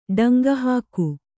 ♪ ḍaŋgahāku